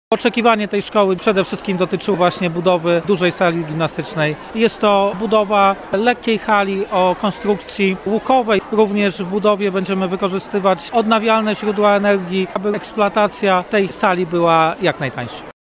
Mówił o tym prezydent Stalowej Woli Lucjusz Nadbereżny.